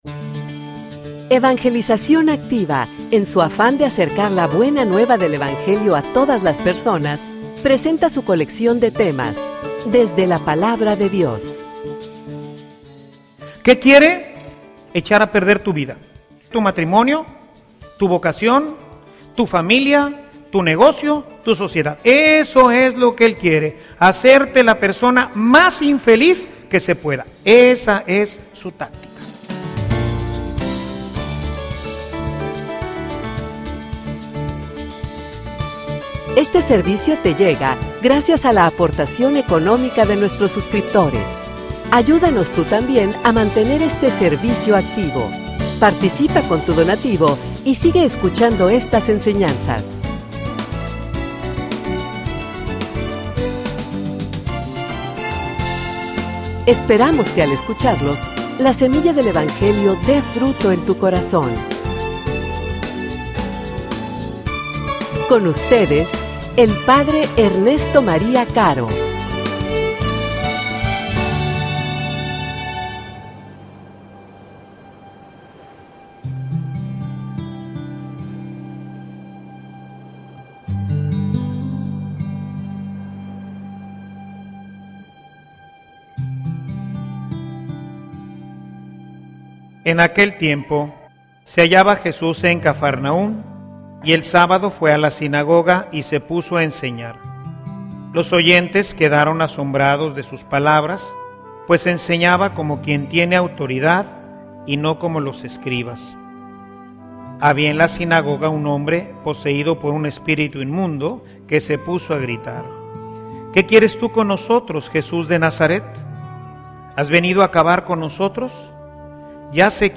homilia_No_le_den_lugar_al_diablo.mp3